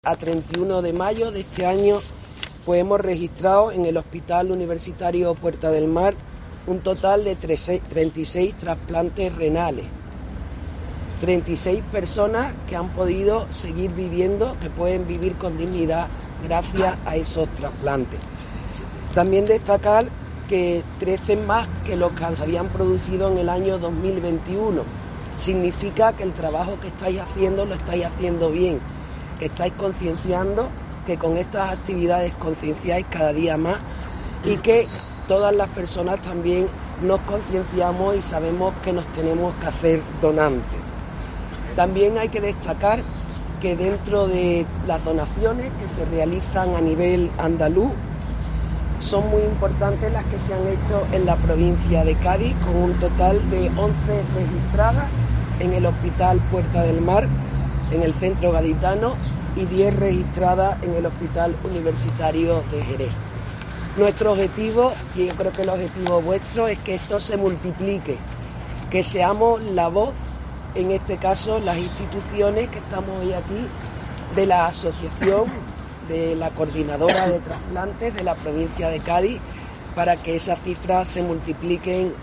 La delegada del Gobierno de la Junta en Cádiz, Mercedes Colombo, habla en el Día del Donante